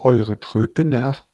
sample02-waveglow.wav